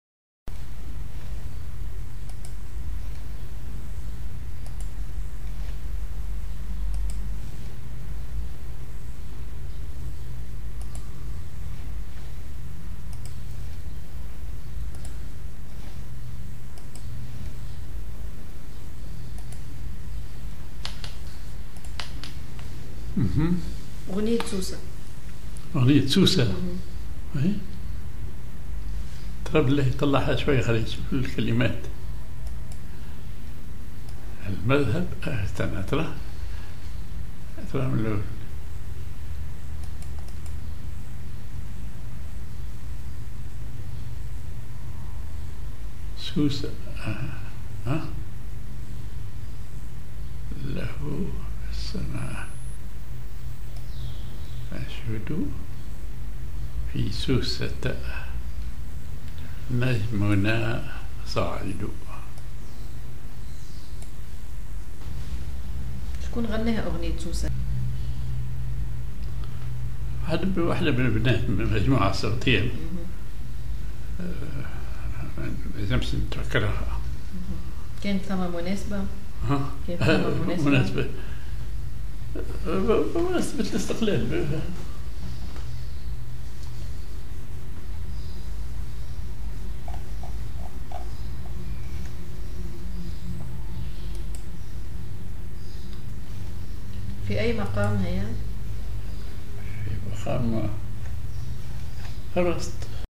genre أغنية